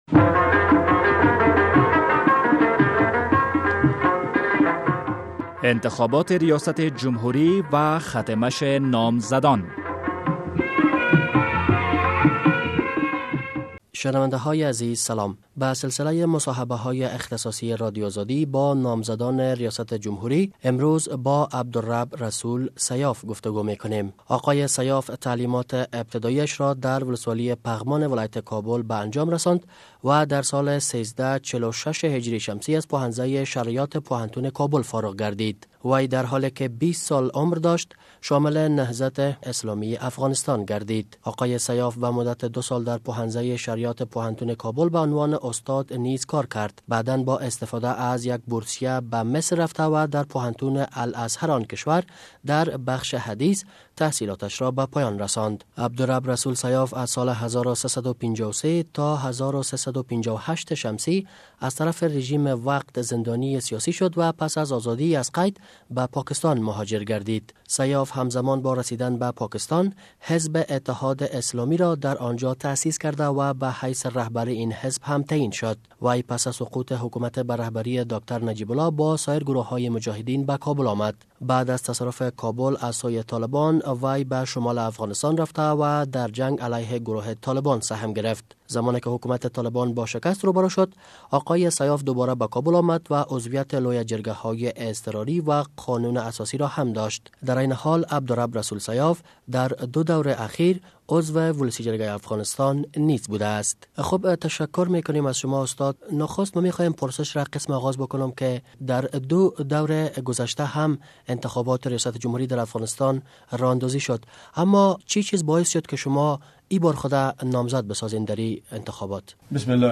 مصاحبهء اختصاصی با عبدرب الرسول سیاف
به سلسلهء معرفی کاندیدان انتخابات ریاست جمهوری افغانستان رادیو ازادی مصاحبه های اختصاصی را با آن ها انجام داده است. اینک مصاحبهء اختصاصی رادیو آزادی را با عبدرب الرسول سیاف می شنوید.